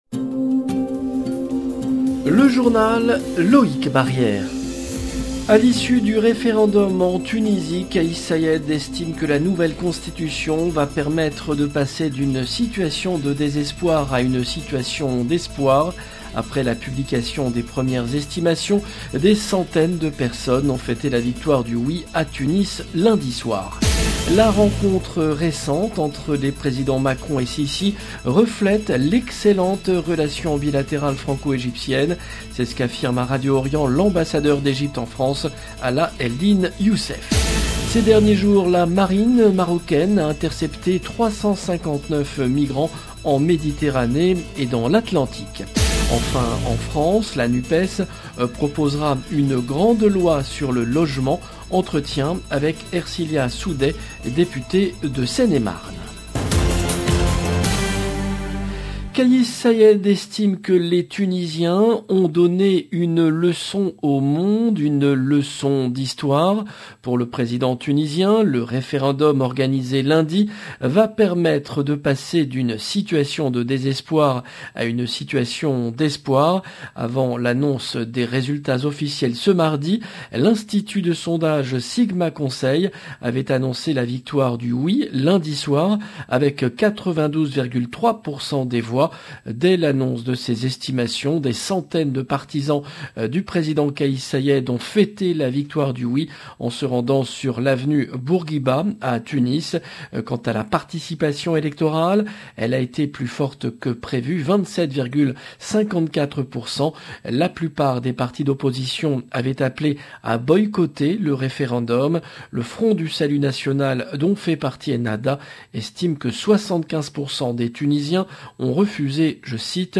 LB JOURNAL EN LANGUE FRANÇAISE
C’est ce qu’affirme à Radio Orient l’Ambassadeur d’Egypte en France, Ala Eldin Youssef Ces derniers jours, la Marine marocaine a intercepté 359 migrants en Méditerranée et dans l'Atlantique. Enfin, en France, la NUPES proposera une grande loi sur le logement. Entretien avec Ersilia Soudais, députée de Seine-et-Marne. 0:00 16 min 25 sec